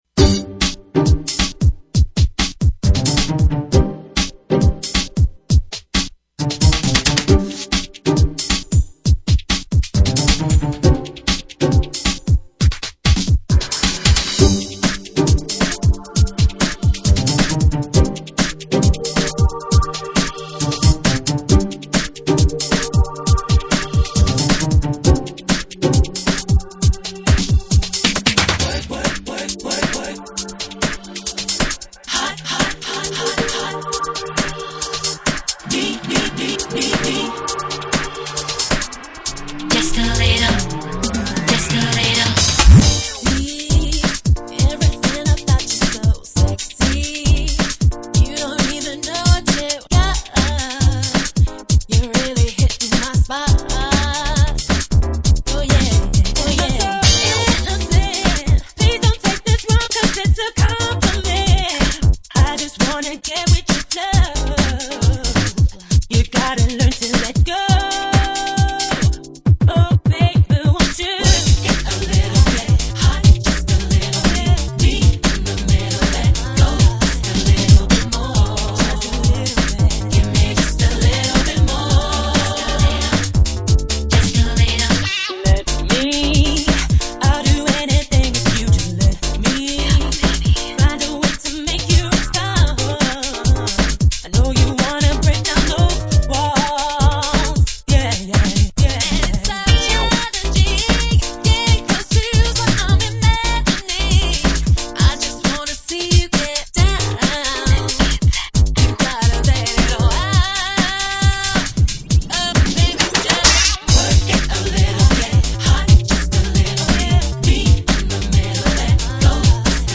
dance/electronic
UK-garage
RnB